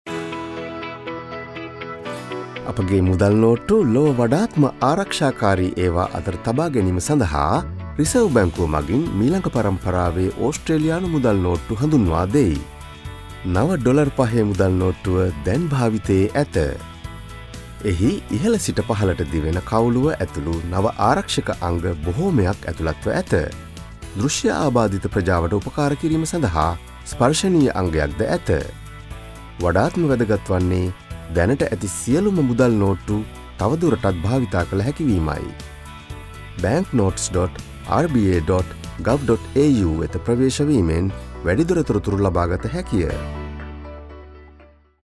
ngb5-radio-ad-translation-sinhalese.mp3